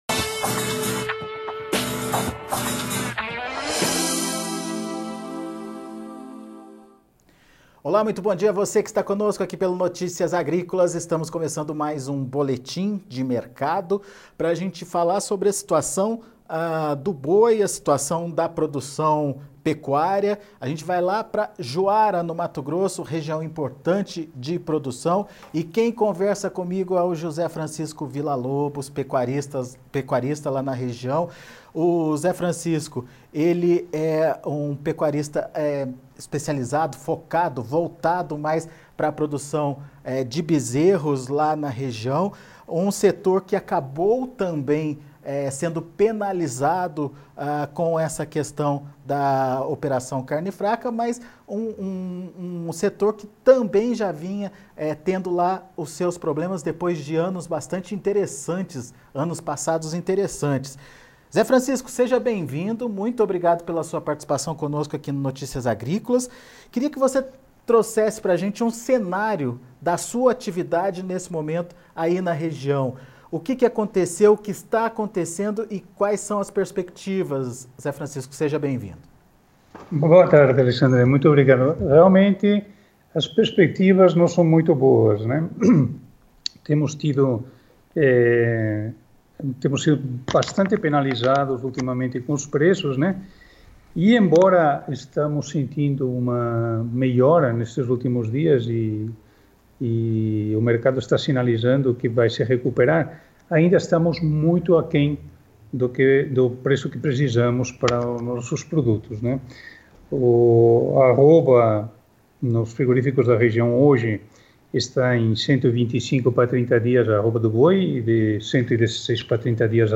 Pecuarista